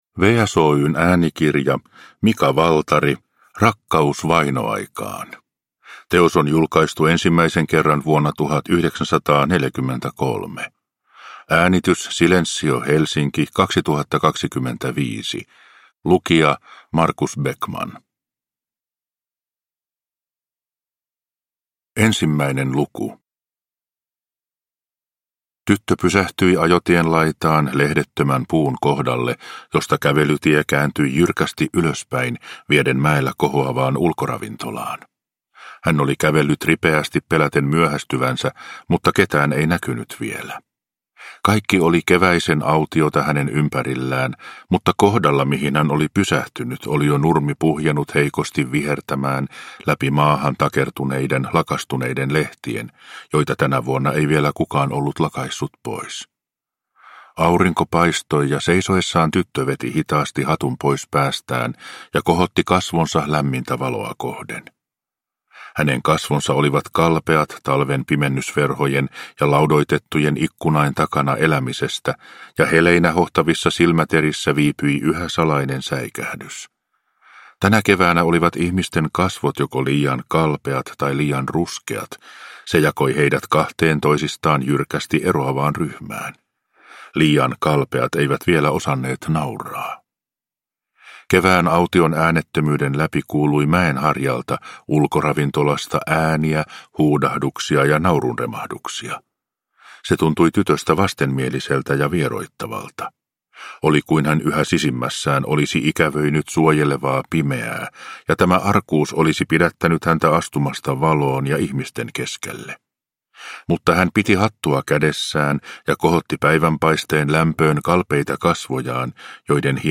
Rakkaus vainoaikaan – Ljudbok